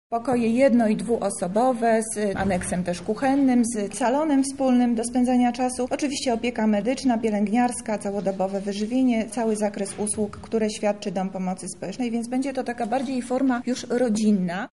Dla stałych pensjonariuszy będą utworzone specjalne oddziały na wzór mieszkania. O szczegółach mówi Monika Lipińska, zastępca prezydenta miasta.